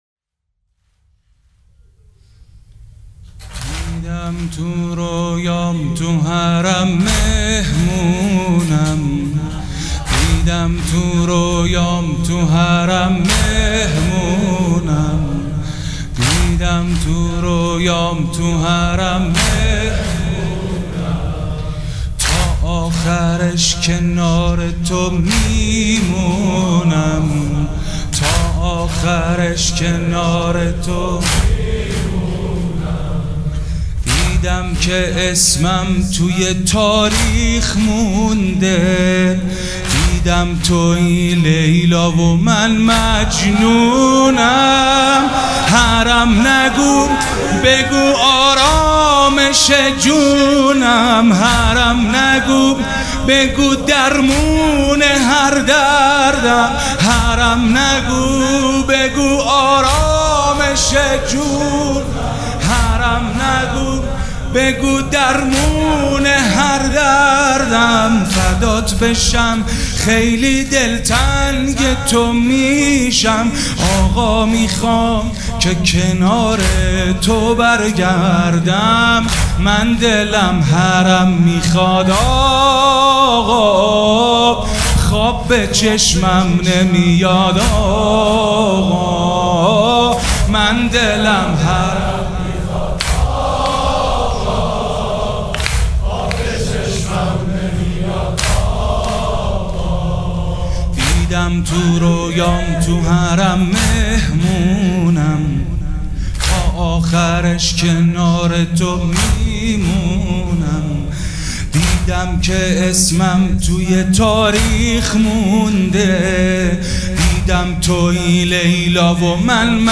مداح
مراسم عزاداری شب چهارم